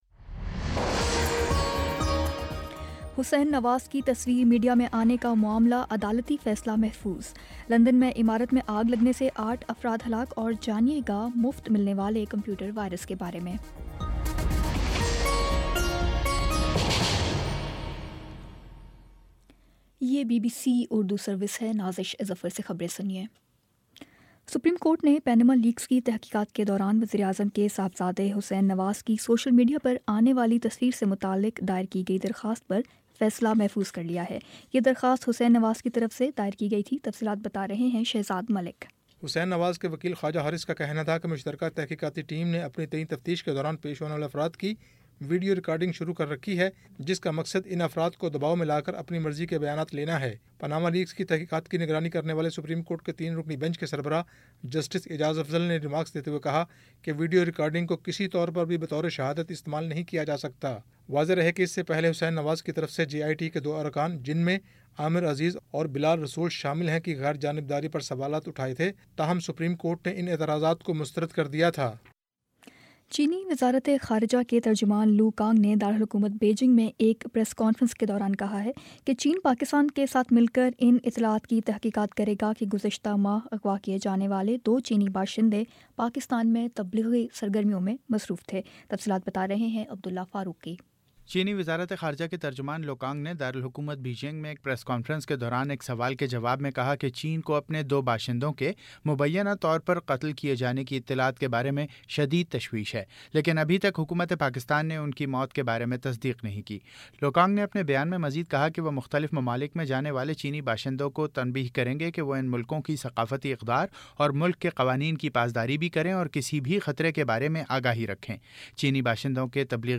جون 14 : شام پانچ بجے کا نیوز بُلیٹن